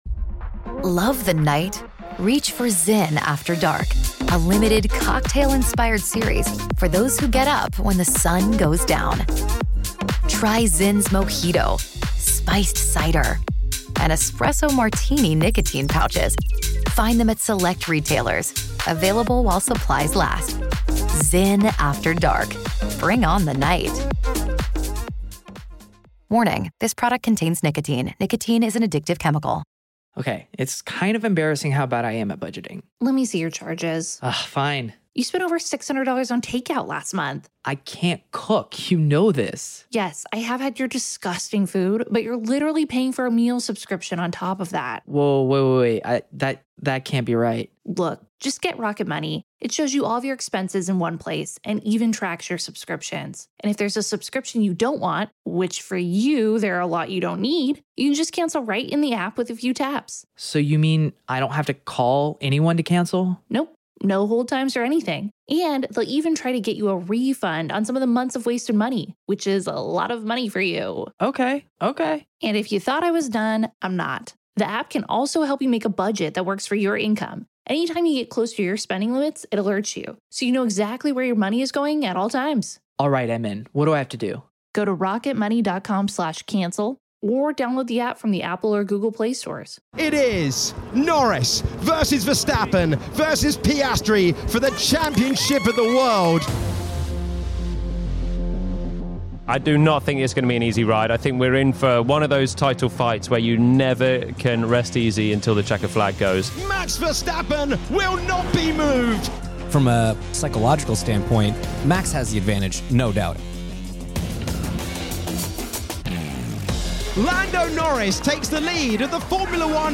F1TV experts Jolyon Palmer and James Hinchcliffe join Tom Clarkson in the Yas Marina paddock to preview this weekend’s thrilling three-way title showdown at the Abu Dhabi Grand Prix.